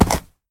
horse_jump.ogg